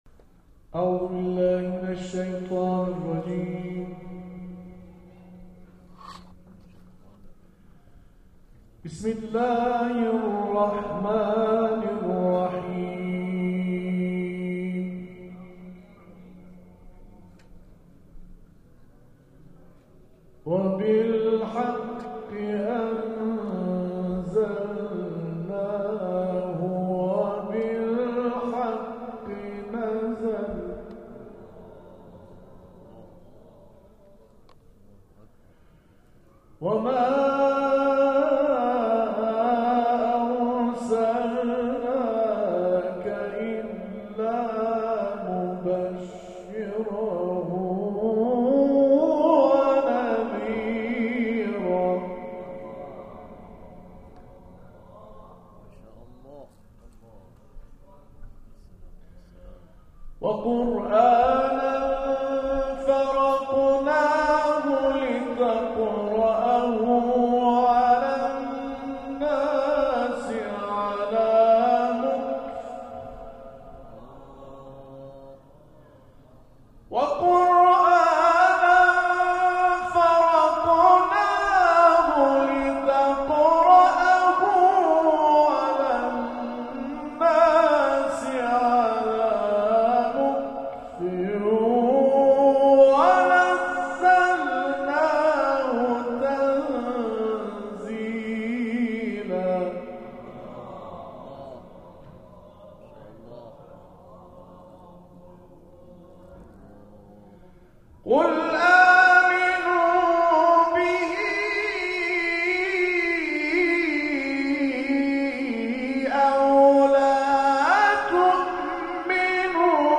به گزارش خبرگزاری بین‌المللی قرآن(ایکنا) به مشهد مقدس، دومین محفل قرآنی کاروان قرآنی ۸۰ نفری فرهنگسرای قرآن با حضور اساتید و قاریان این کاروان، در دارالقرآن حرم مطهر امام رضا(ع)، در جوار مضجع شریف سلطان طوس، از ساعت 19 الی 20:30 برگزار شد.